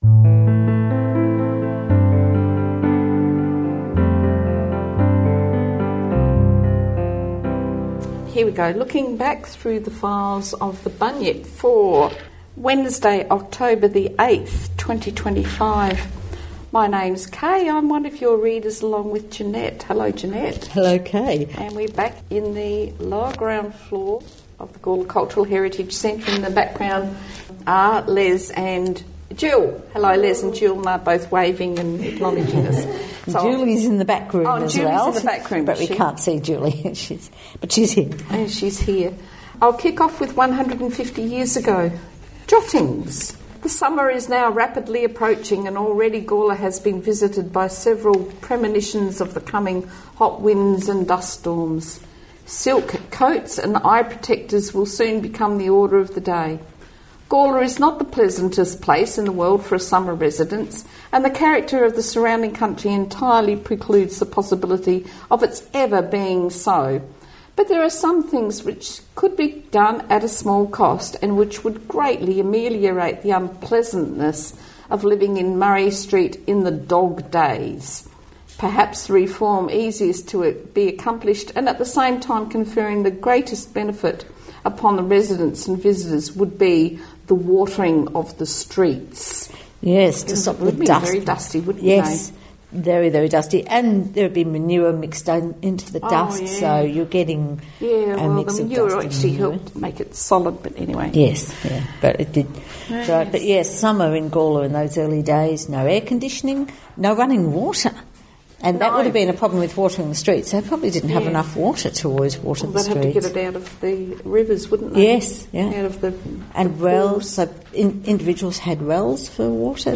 Opening and closing music